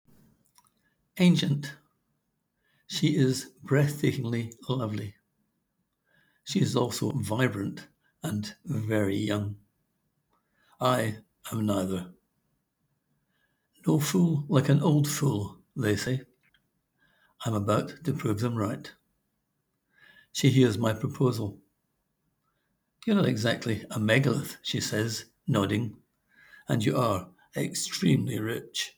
Click here to hear the author read his words: